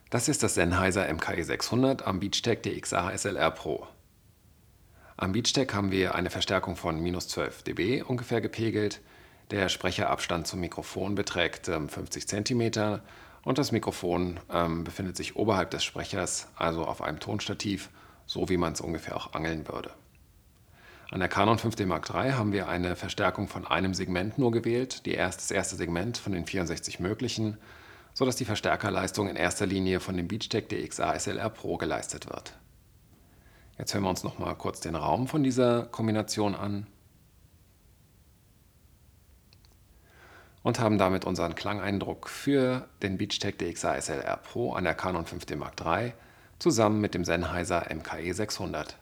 Der Abstand zwischen Richt-Mikrofon und Sprecher beträgt stets 0,5m. Das Mikro wurde auf einem Tonstativ oberhalb des Sprechers montiert.
Sennheiser MKE 600 via Beachtek DXA-SLR PRO an Canon EOS 5D Mark III